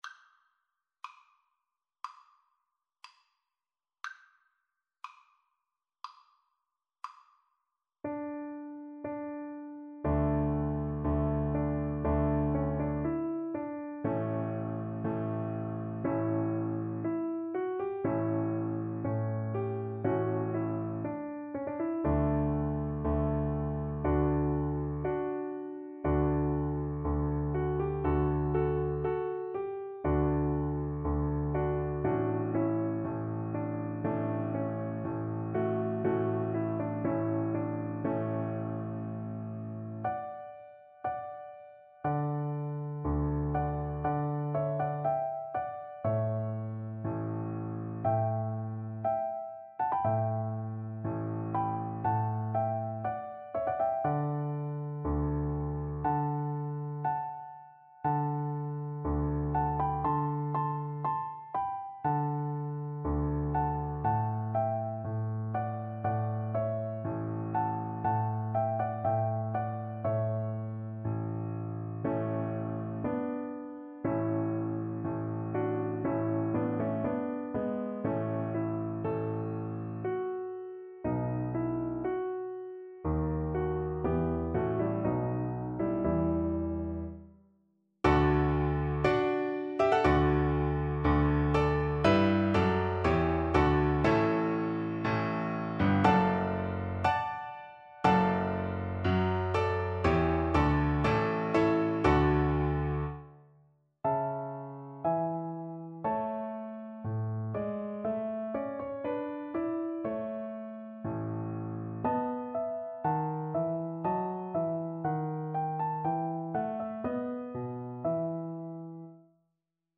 Slow =c.60
Classical (View more Classical Violin Music)